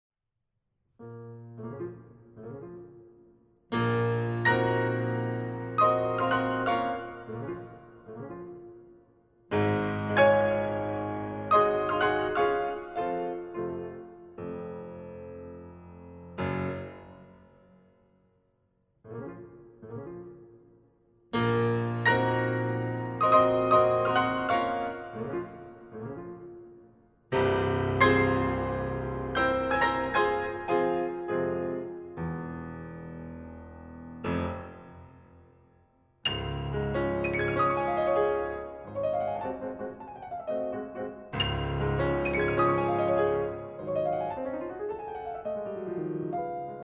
拍というより小節でテンポを刻むくらいの急速さです。
拍でテンポを刻もうとすると、プルプルプルプル、大忙しです。
・演奏の速さは♩=２２０くらい（ざっくり、プレストの速度が１７０〜２２０あたりで示される数値との比較ではさらに速い）